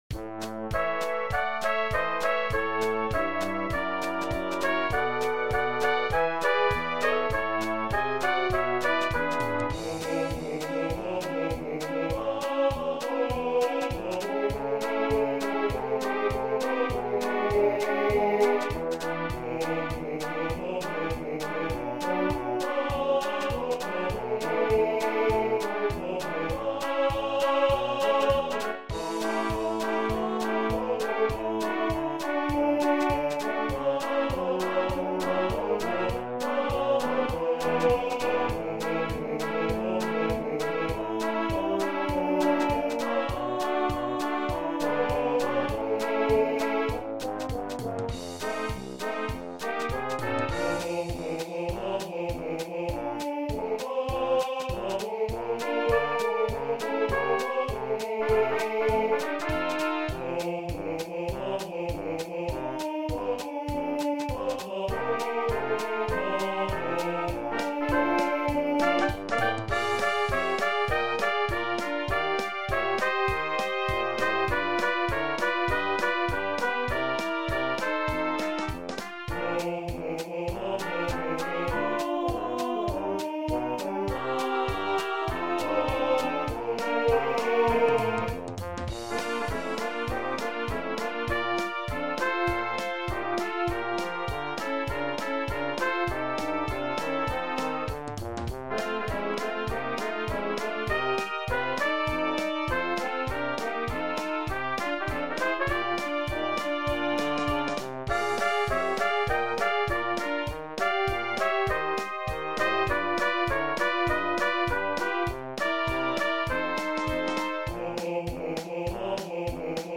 Arranged for Brass Quartet, Vocals and Drums.
Fun Christmas tune that’s both traditional and novelty.